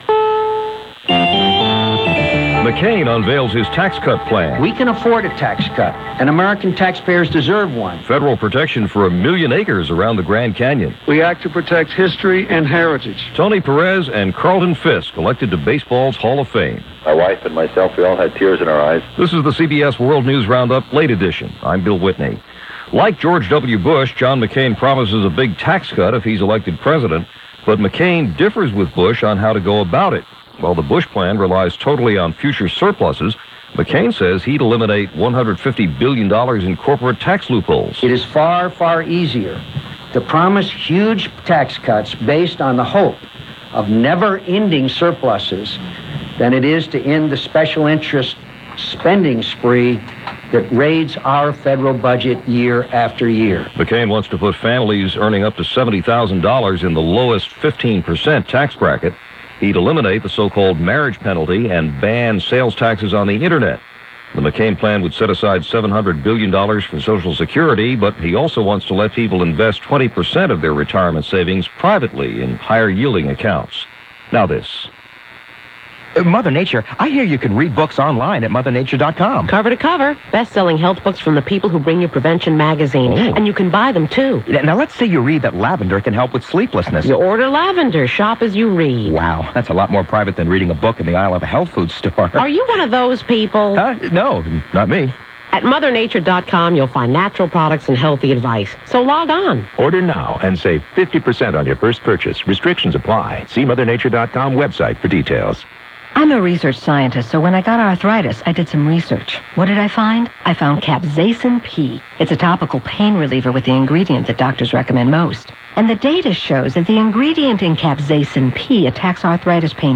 And that’s just a small slice of what happened, this January 11, 2000 as reported by the CBS World News Roundup Late Edition.